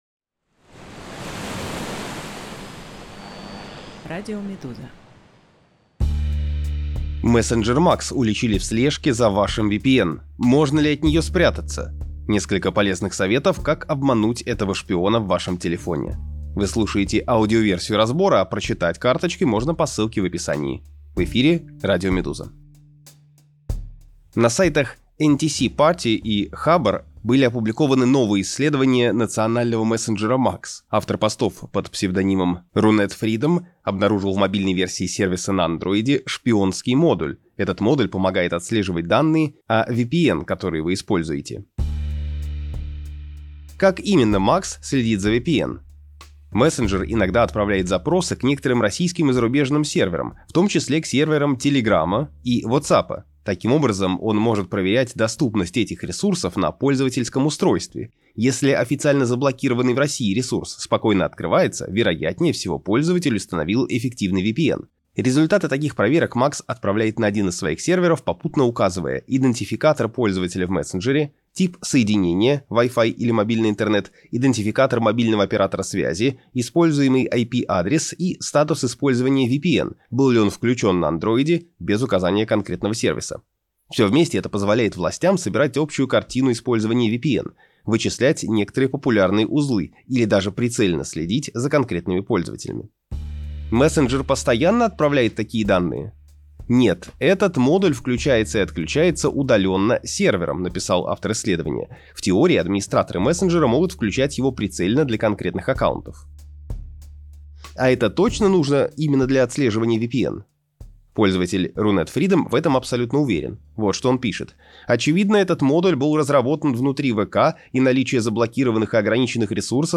Аудиоверсия разбора.